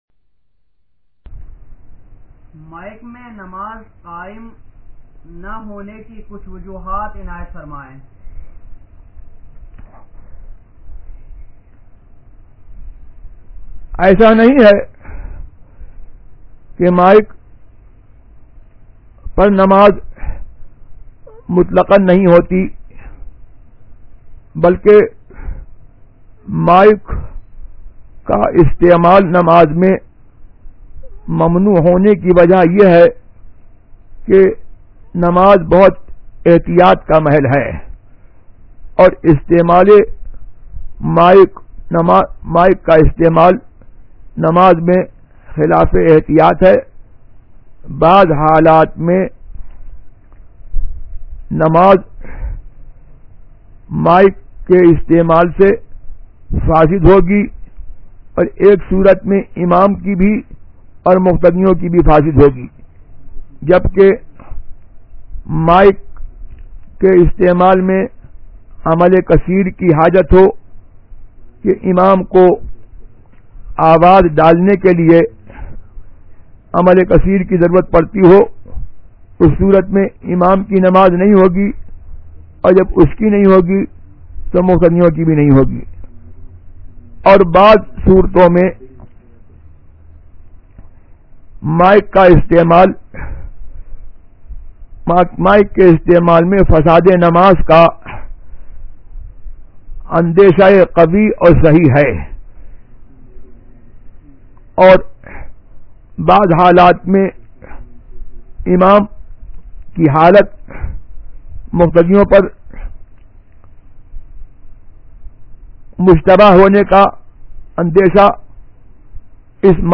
Answer (Voice Recording):